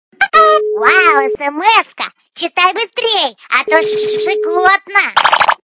» Звуки » звуки для СМС » Вау, СМС-ка! - Читай быстрей, а то щекотно
При прослушивании Вау, СМС-ка! - Читай быстрей, а то щекотно качество понижено и присутствуют гудки.